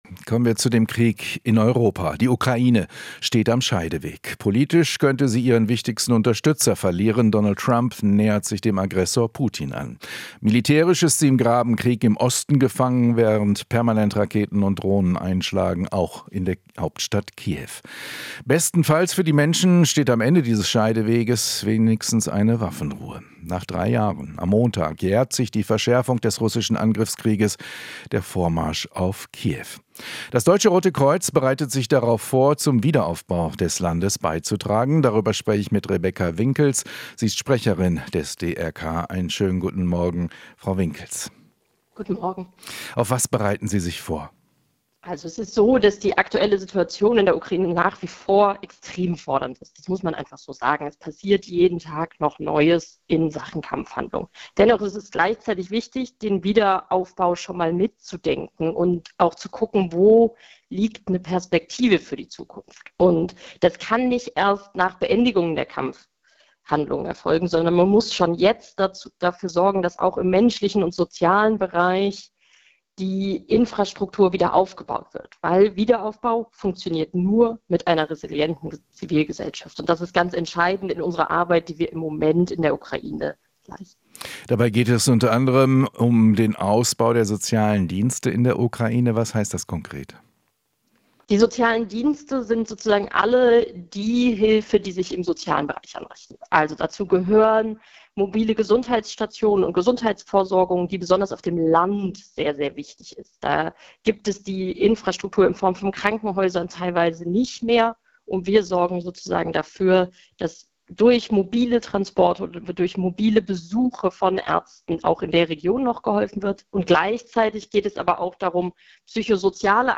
Interview - DRK: Wiederaufbau schon jetzt mitdenken